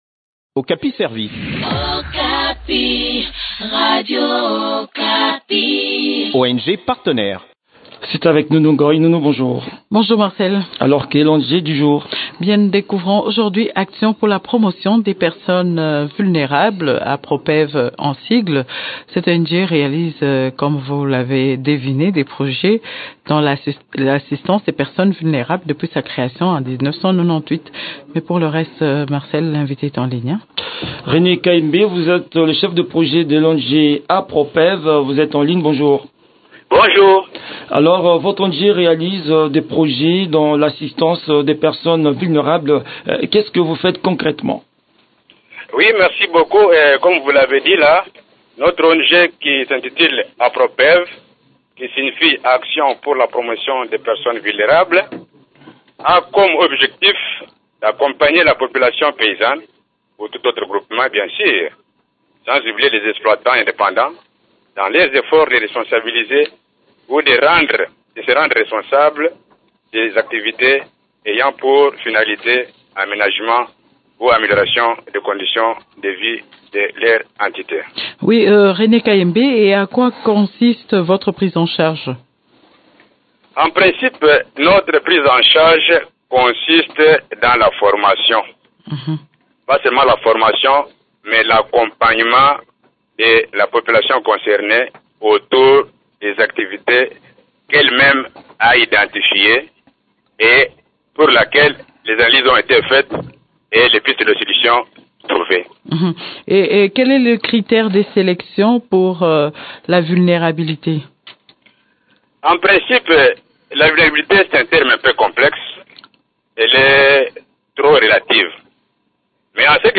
Elle exécute aussi des projets dans les secteurs des infrastructures, agriculture et environnement. Le point des activités de cette structure dans cet entretien